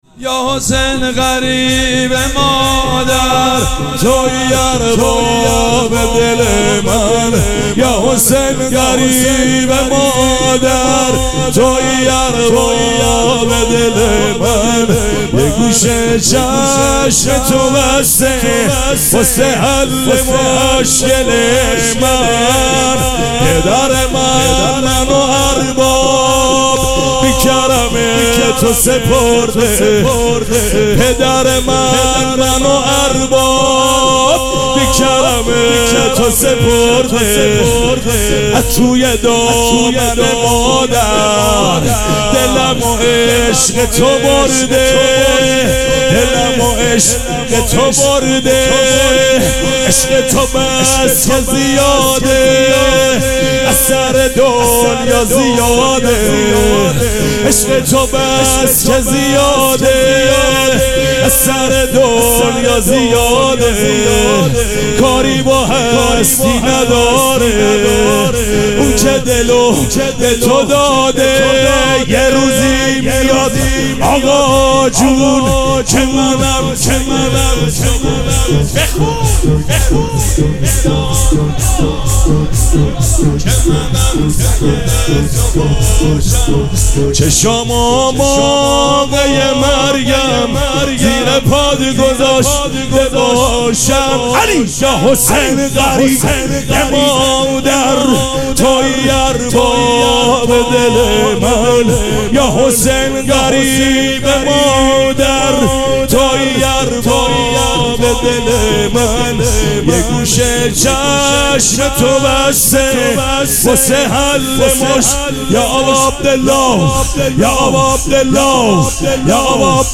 شب پنجم مراسم عزاداری اربعین حسینی ۱۴۴۷
شور
مداح
مراسم عزاداری اربعین حسینی